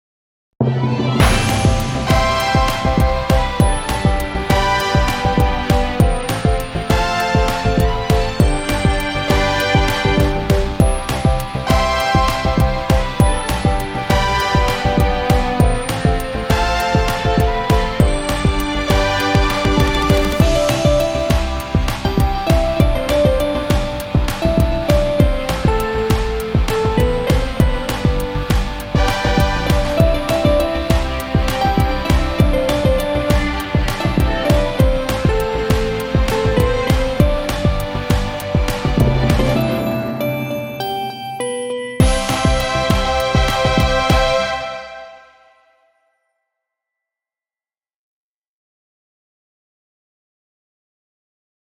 ショートポップ明るい
BGM